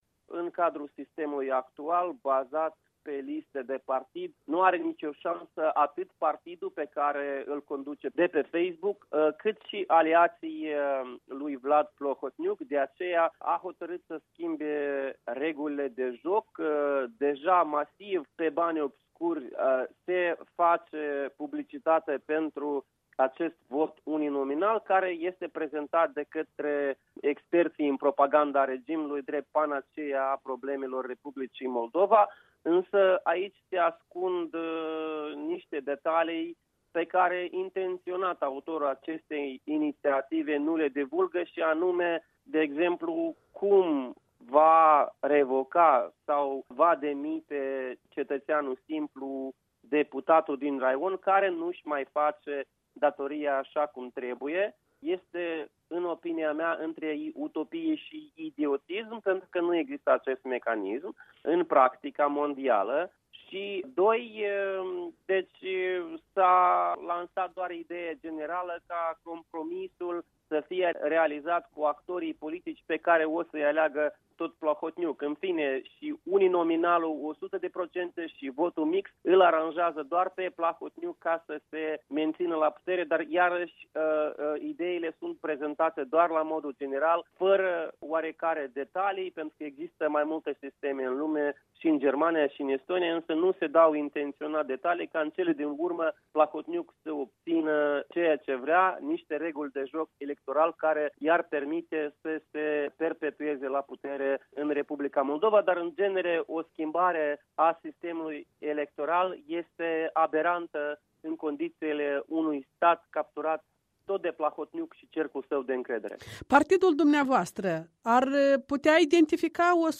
Dialog cu unul din fruntașii Partidului Nostru despre reforma sistemului electoral.